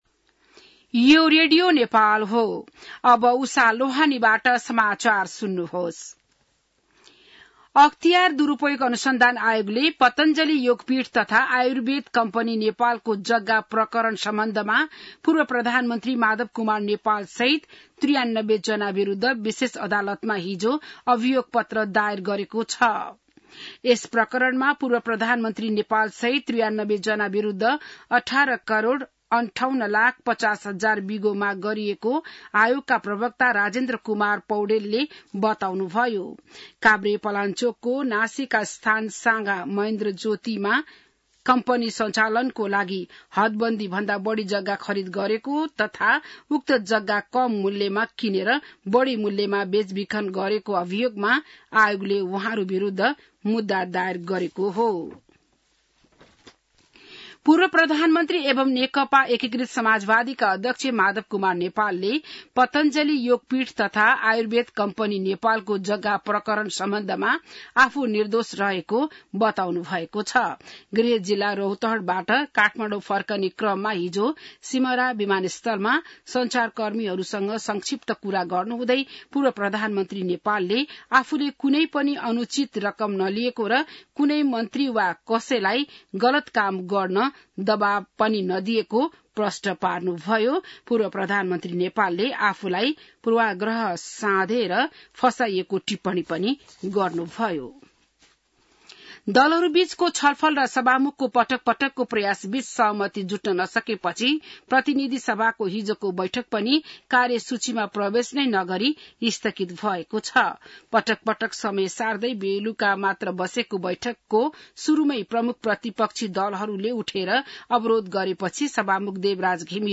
बिहान १० बजेको नेपाली समाचार : २३ जेठ , २०८२